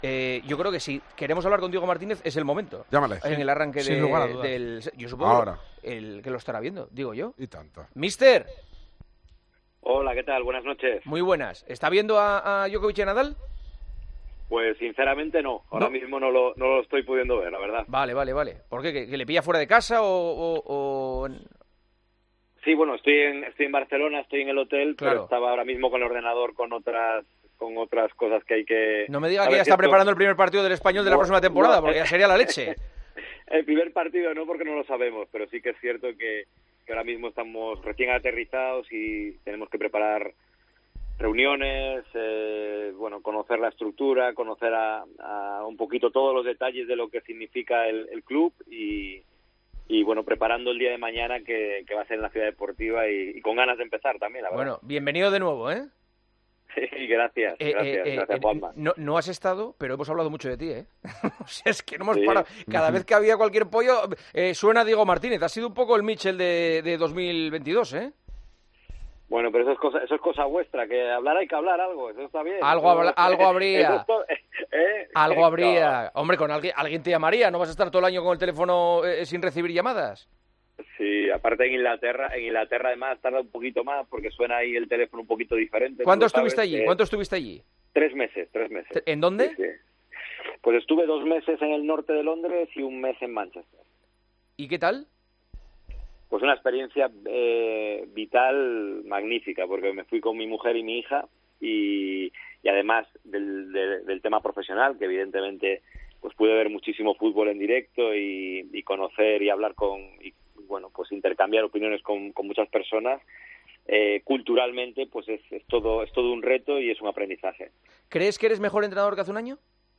ENTREVISTA DIEGO MARTÍNEZ, EN EL PARTIDAZO DE COPE